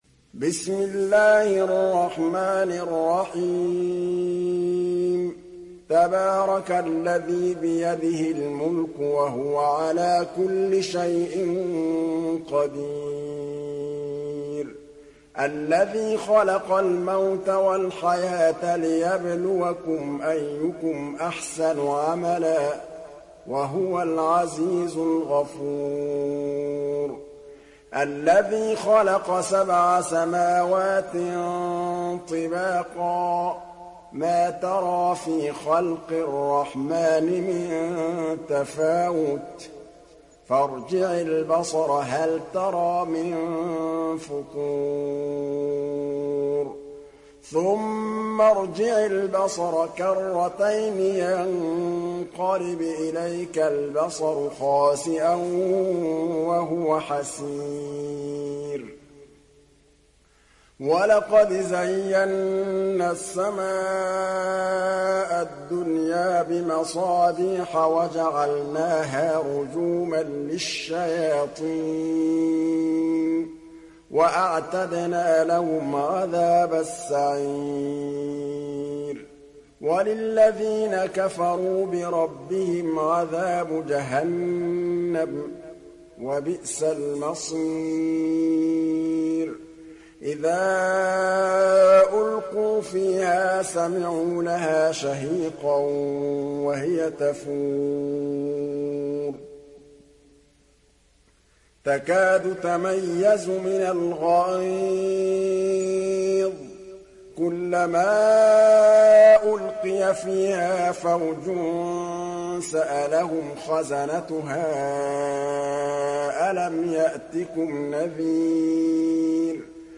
Surat Al Mulk Download mp3 Muhammad Mahmood Al Tablawi Riwayat Hafs dari Asim, Download Quran dan mendengarkan mp3 tautan langsung penuh